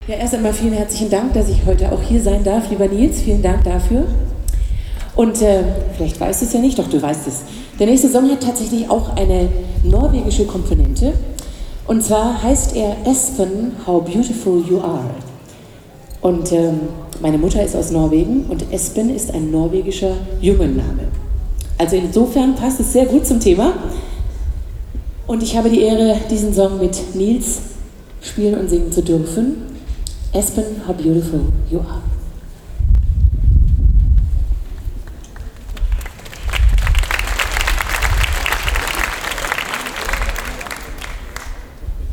Konzert Regionalabend 31. Jazz im Brunnenhof (Trier)
09 - Ansage.mp3